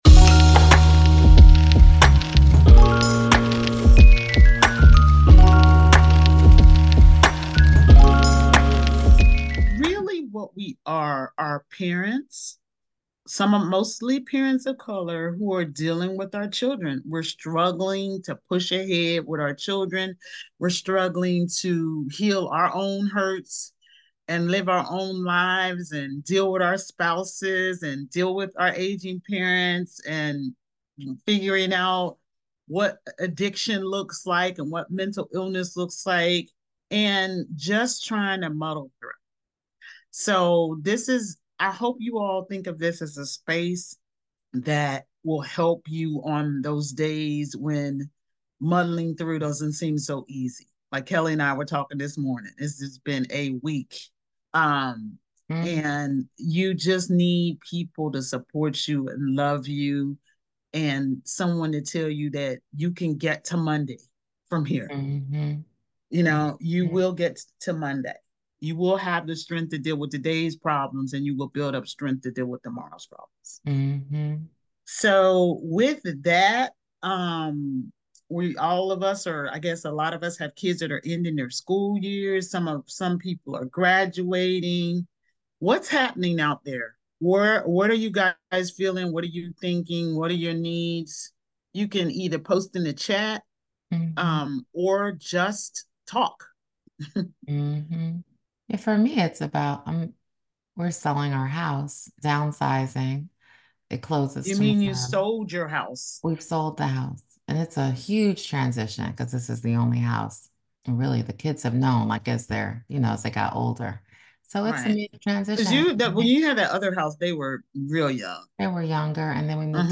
we're having an Open Session where the audience leads the conversation.